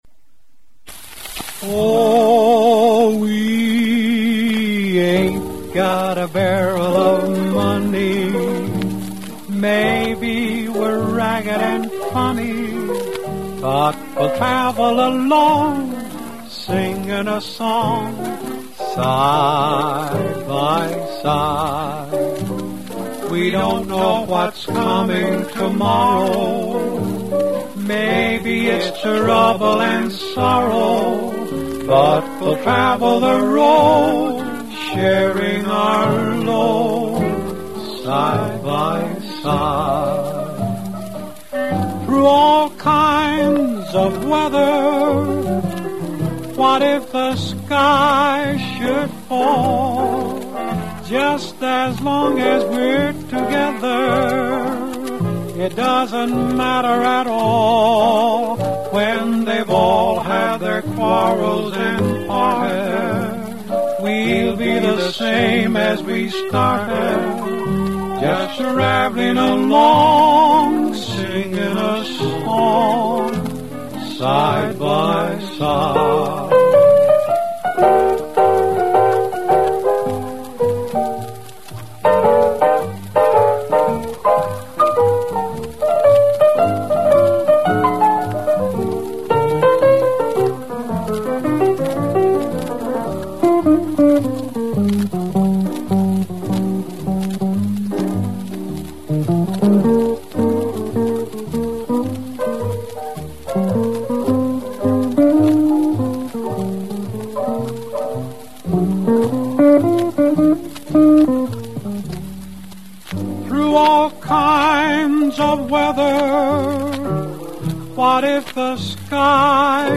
making it among the first multi-track recordings in history.
acoustic
fine jazzy guitar solo containing interesting bass lines